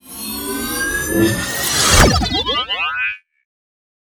beam.wav